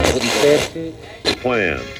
120BPMRAD1-L.wav